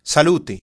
generic toast salute /sa.ˈlu.te/ (sall-OO-teh) (
It-salute.ogg